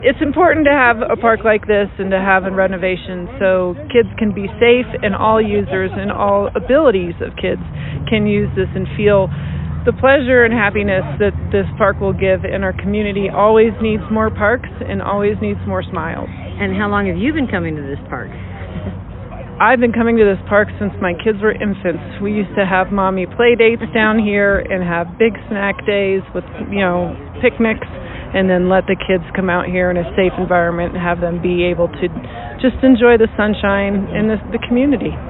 She made the presentation of the new playground on Tuesday.